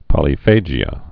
(pŏlē-fājē-ə, -jə) or po·lyph·a·gy (pə-lĭfə-jē)